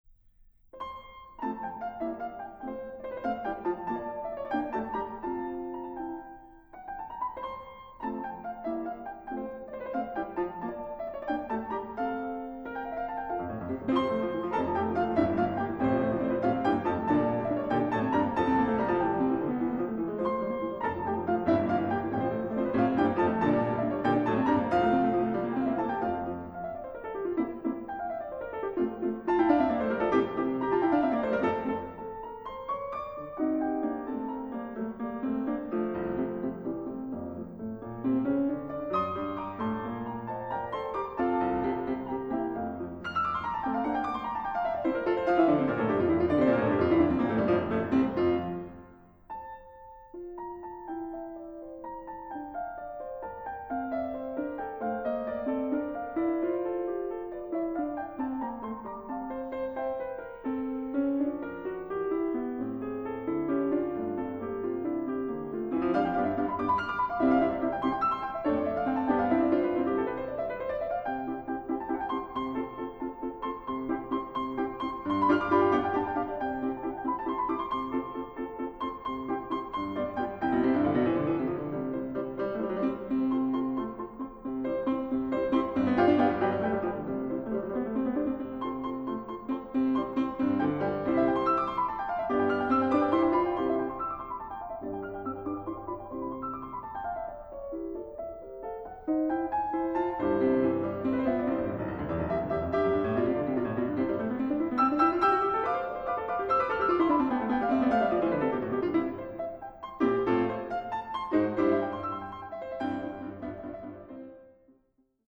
for piano 4 hands